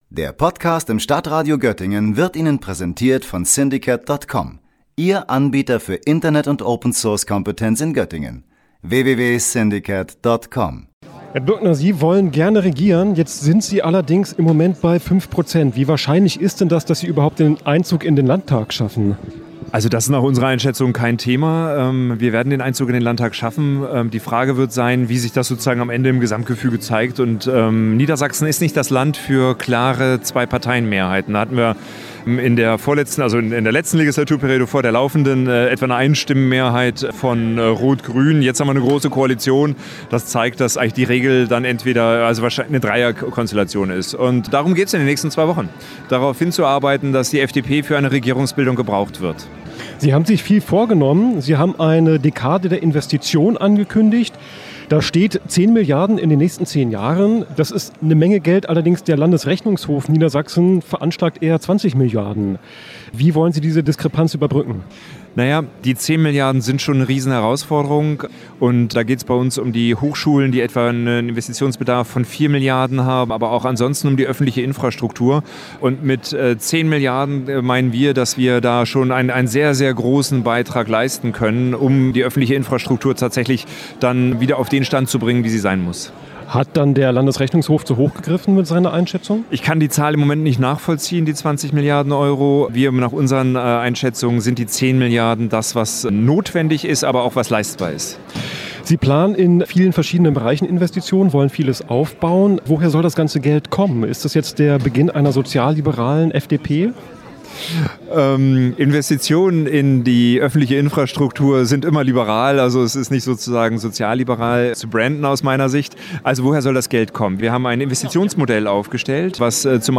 Darum sprachen wir mit ihm ausführlicher darüber, was seine Partei für Niedersachsen geplant hat. Unter anderem 10 Milliarden Euro sollen inverstiert werden.